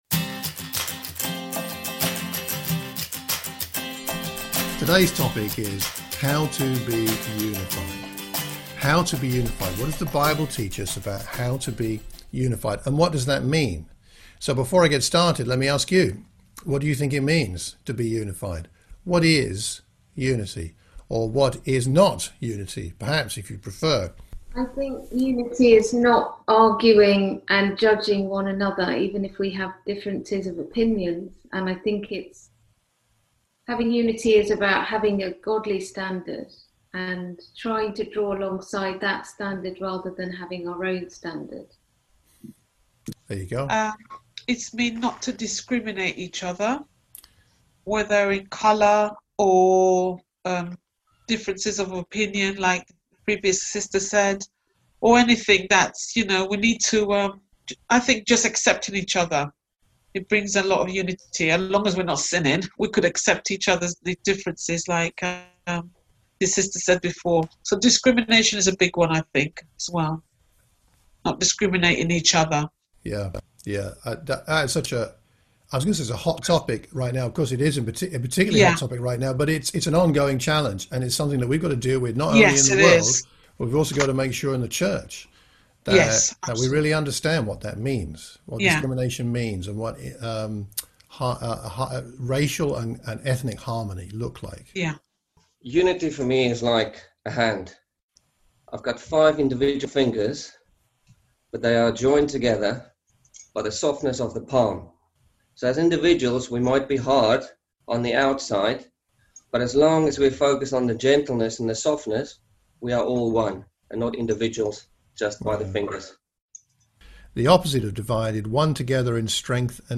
A sermon for the Watford church of Christ